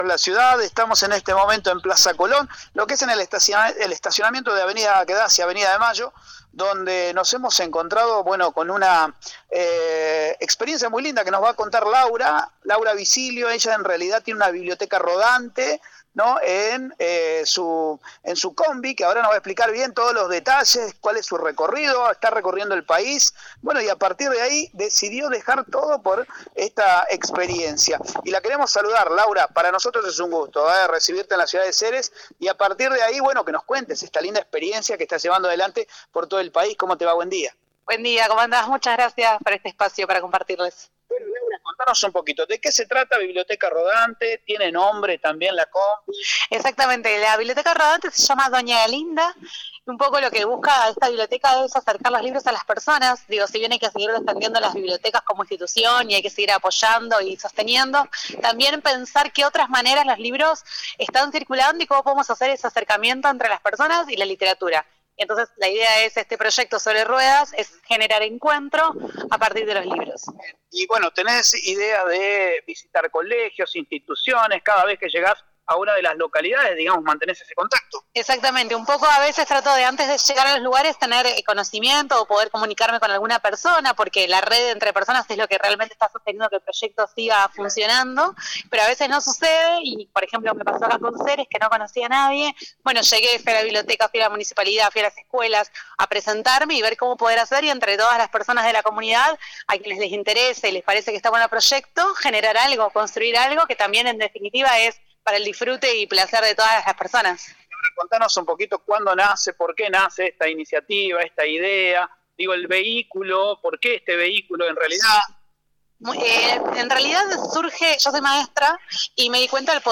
Esta mañana en un móvil entrevistamos